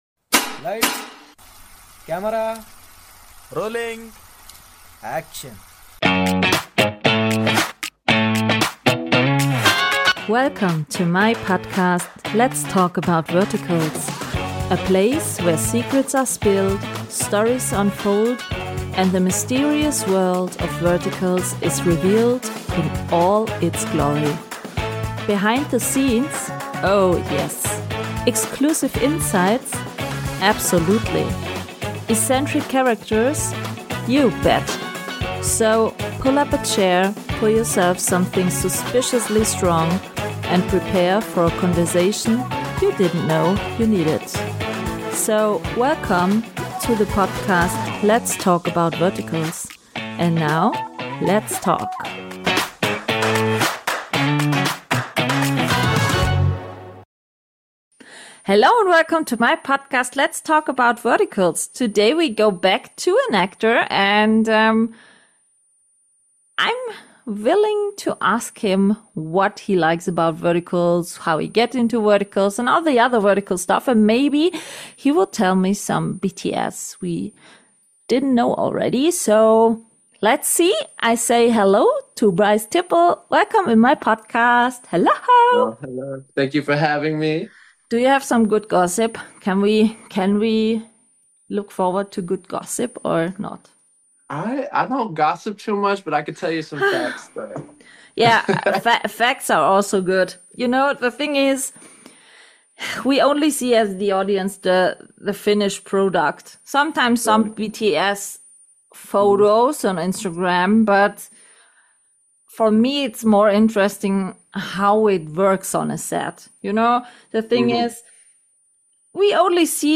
An inspiring conversation about courage, creativity, collaboration — and staying open to where life takes you.